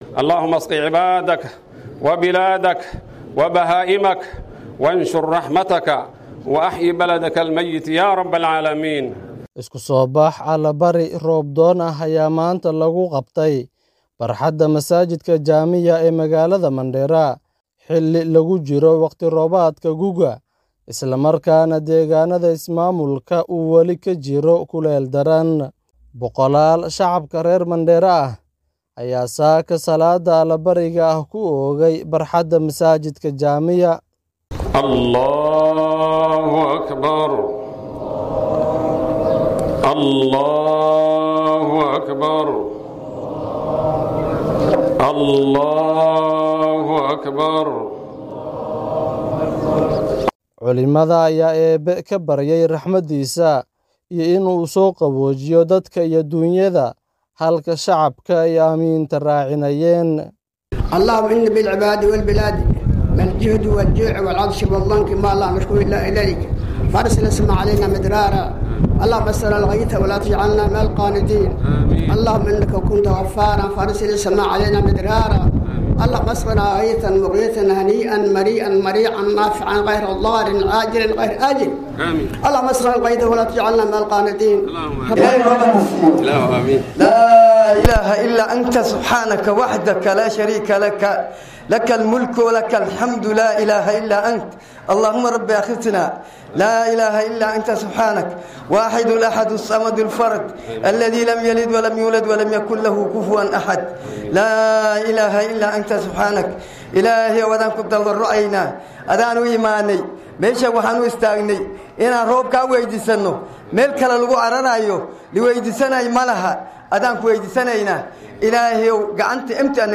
Magalada mandera ayaa lagu qabtay isku soo bax alle bari roob doon ah oo ay kasoo qeyb galeen boqolal shacab ah.